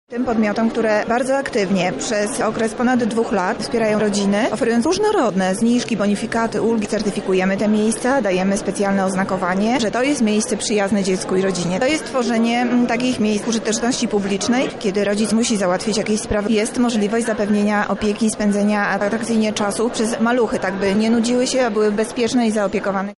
Jak wygląda to współdziałanie mówi Monika Lipińska, zastępca prezydenta miasta do spraw socjalnych.